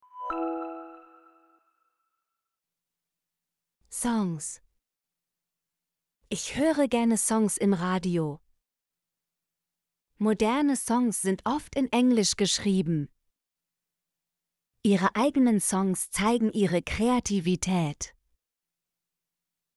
songs - Example Sentences & Pronunciation, German Frequency List